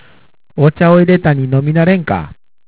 方言